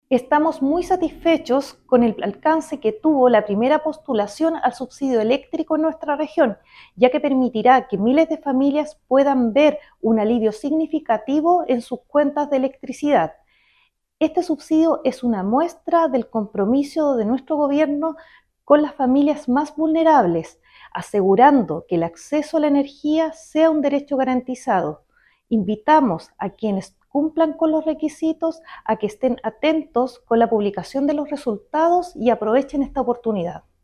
La seremi de Energía, Daniela Espinoza, explicó que el Subsidio Eléctrico es una herramienta clave para garantizar que las familias más vulnerables del país puedan hacer frente a sus necesidades básicas sin preocuparse por el pago de sus cuentas de luz.
seremi-de-energia.mp3